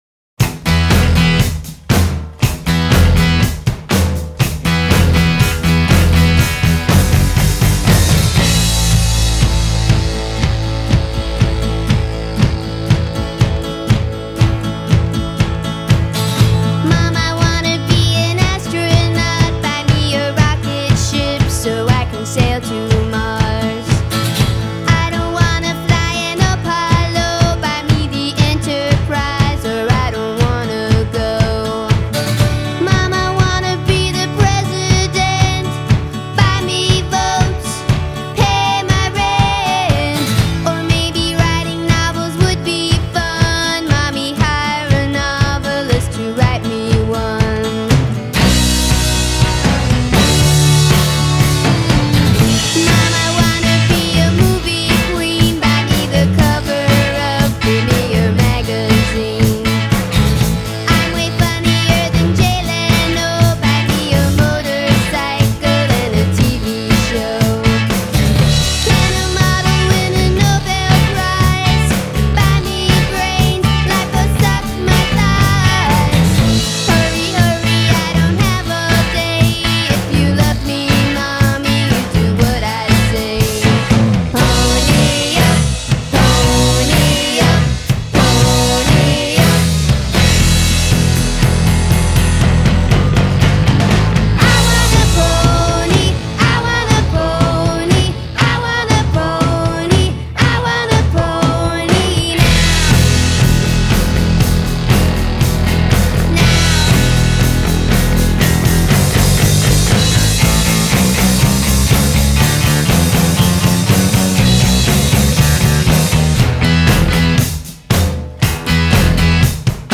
superior pop band